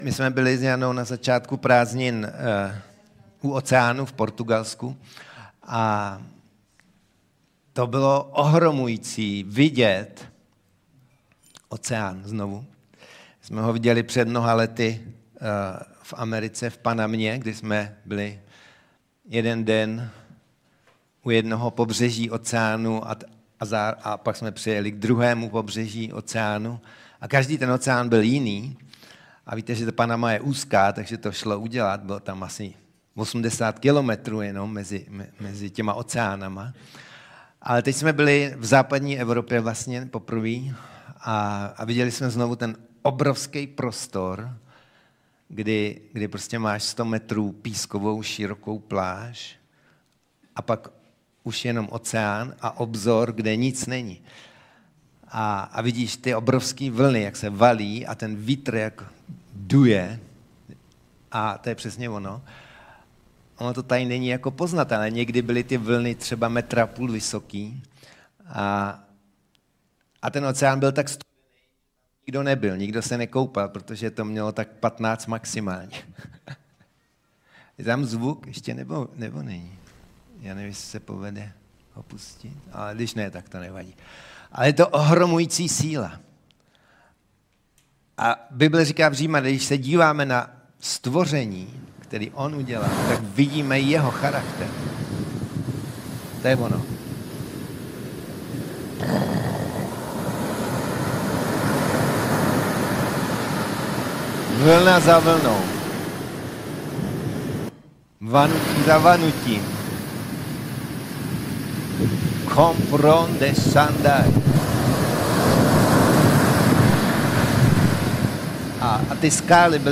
Bohoslužby Slova života Pardubice